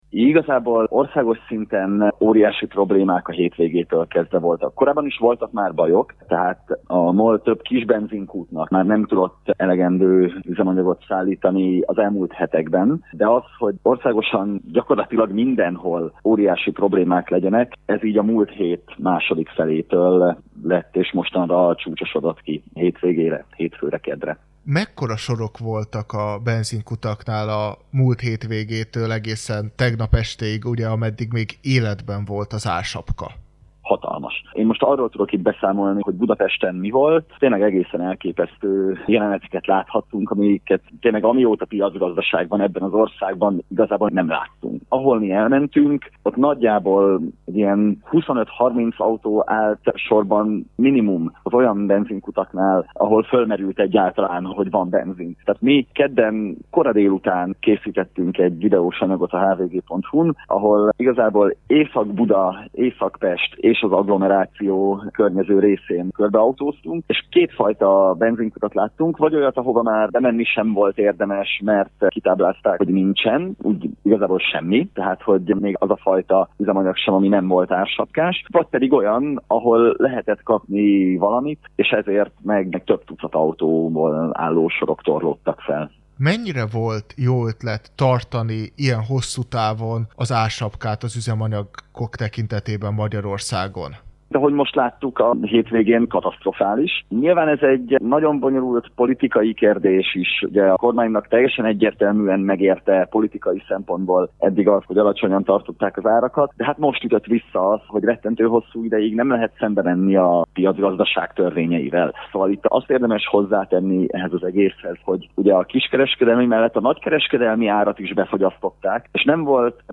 A magyarországi lakosok tegnap estétől kénytelenek egy közel másfélszeres árat fizetni. A fentiekről beszélgettünk